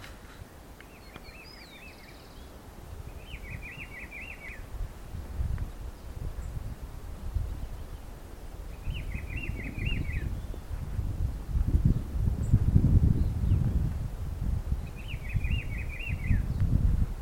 Rufous-browed Peppershrike (Cyclarhis gujanensis)
Se observo una pareja en Cortejo, el macho es el que canta.
Location or protected area: Parque Nacional Ciervo de los Pantanos
Certainty: Observed, Recorded vocal